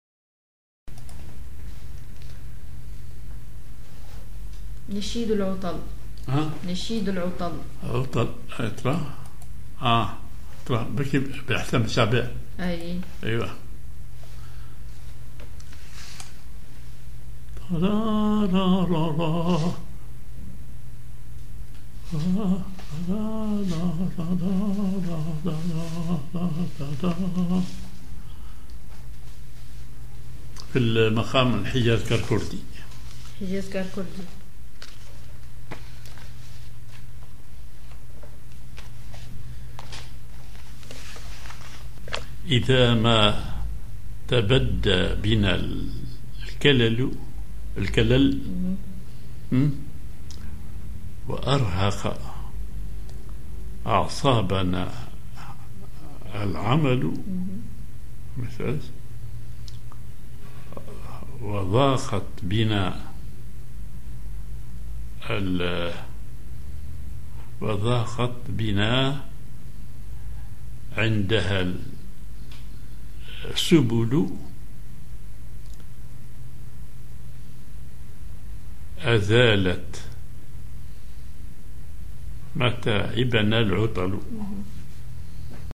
Maqam ar حجاز كار كردي
genre نشيد